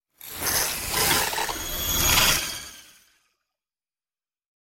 Звуки аномалий
Шум возникновения аномалий